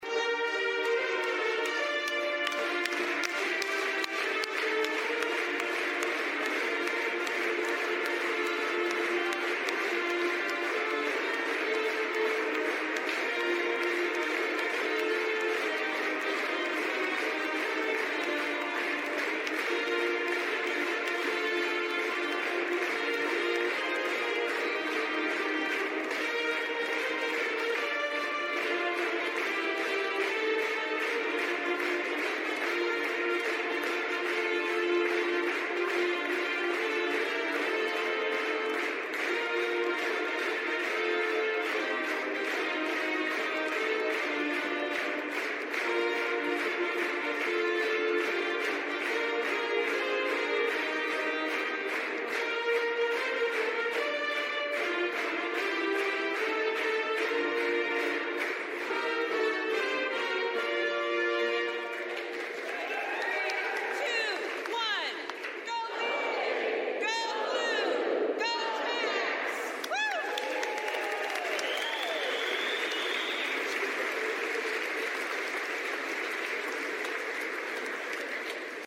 The inaugural ceremony today (Jan. 7, 2023) at the State Capitol in Pierre ended with a show of support for the South Dakota State University football team. A brass group played the Jacks’ school song, which was followed by Gov. Kristi Noem leading the “Go Big. Go Blue. Go Jacks!” cheer.